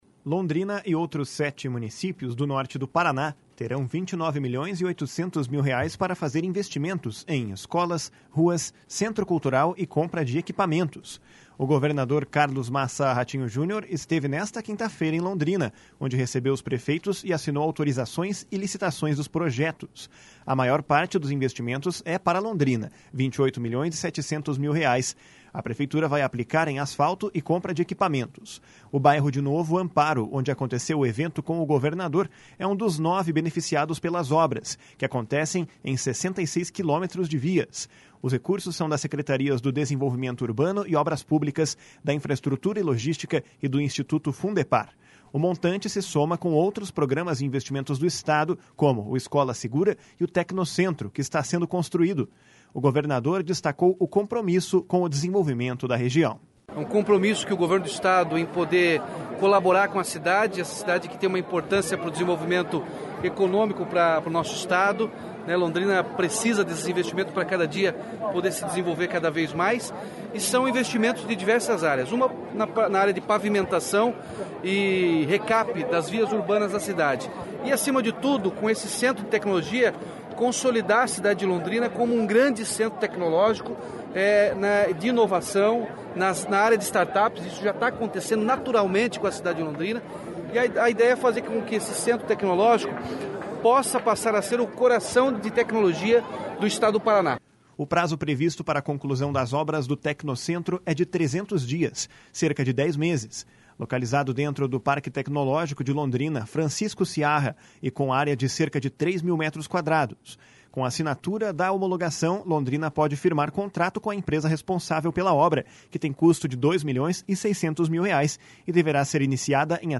Londrina e outros sete municípios do Norte do Paraná terão 29 milhões e 800 mil reais para fazer investimentos em escolas, ruas, centro cultural e compra de equipamentos. O governador Carlos Massa Ratinho Junior esteve nesta quinta-feira em Londrina, onde recebeu os prefeitos e assinou autorizações e licitações dos projetos.
// SONORA RATINHO JUNIOR //
// SONORA MARCELO BELINATI //